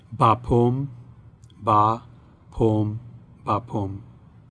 Bapaume French pronunciation: [bapom]
Bapaume_pronunciation.ogg.mp3